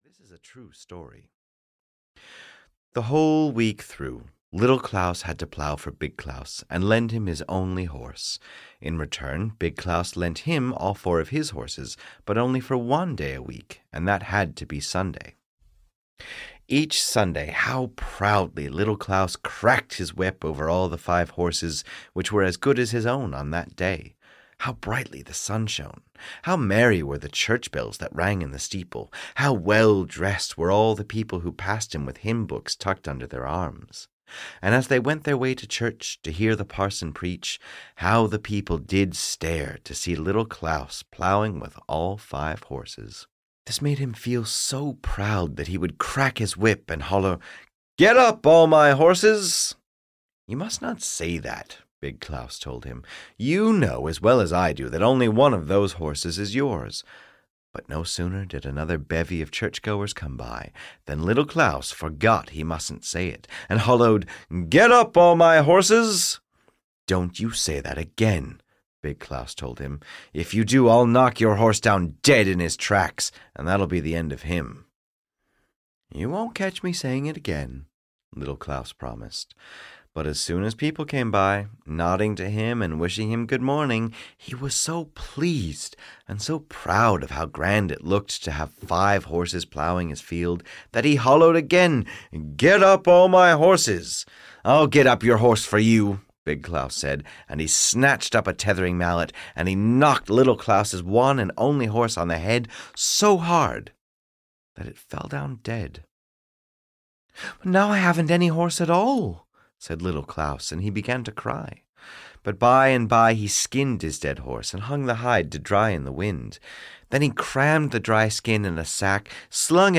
Little Claus and Big Claus (EN) audiokniha
Ukázka z knihy